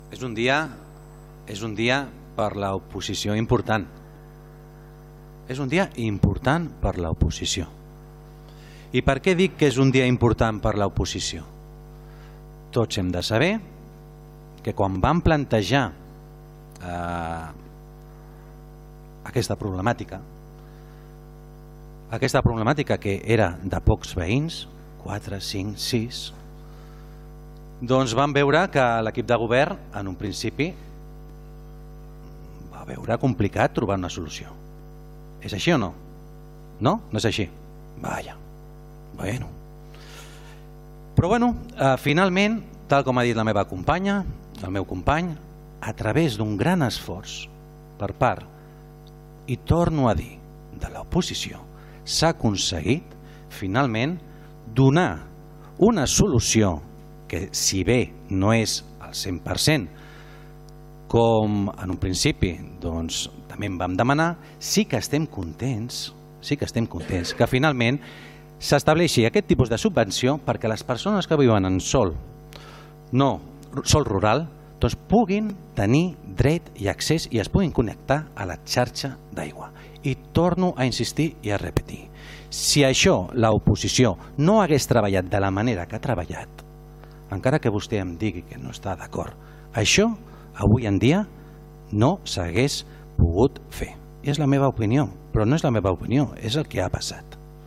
Per la seva part, el portaveu del PSC, Miquel Santiago, va destacar el paper de l’oposició i l’esforç per aconseguir trobar una solució: